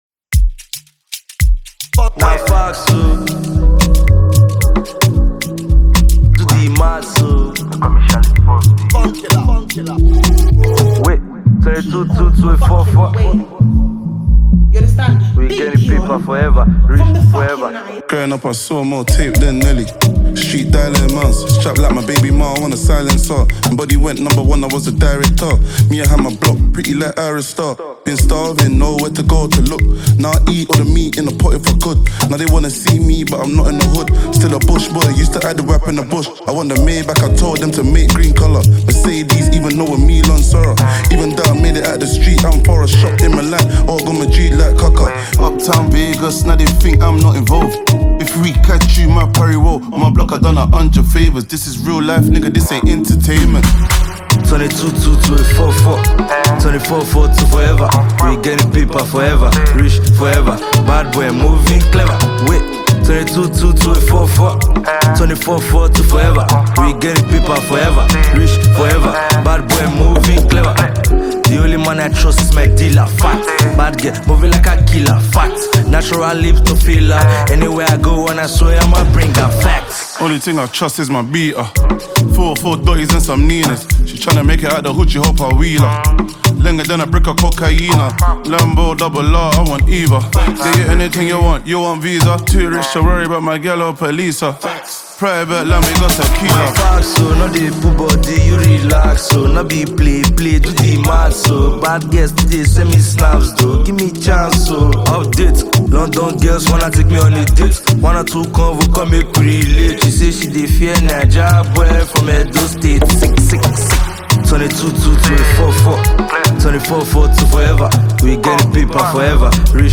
British Rapper